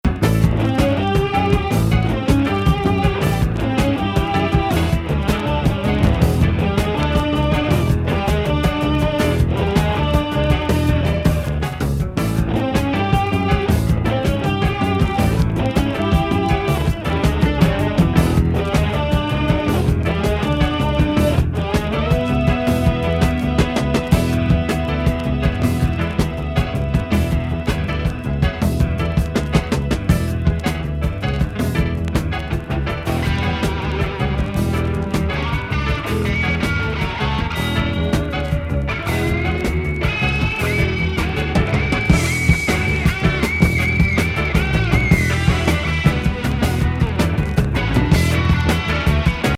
ほっこりレゲー